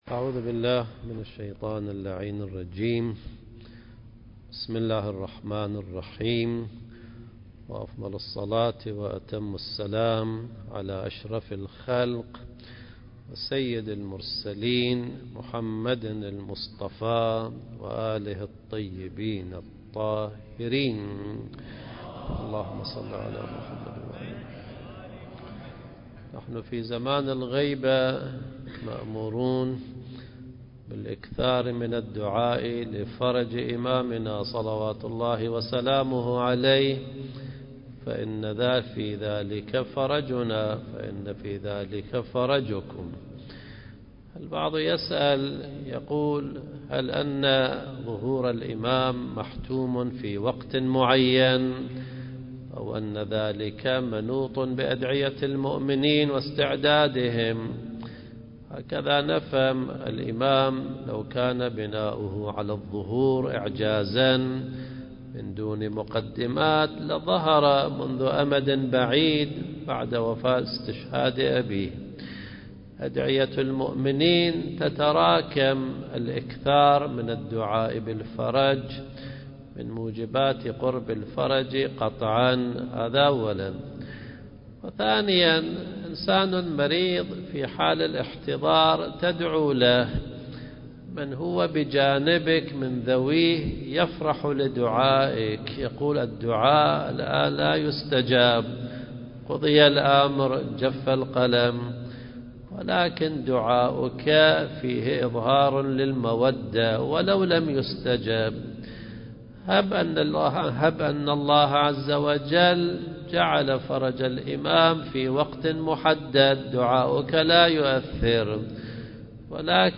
المكان: مؤسسة المجتبى (عليه السلام) للثقافة والإرشاد